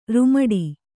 ♪ rumaḍi